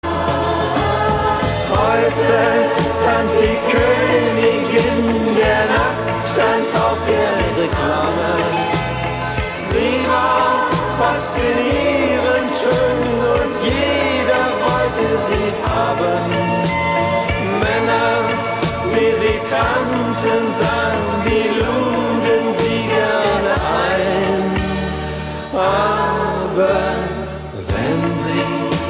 Ein gefühlvoller Titel
Tonart: B-Dur
Besetzung: Blasorchester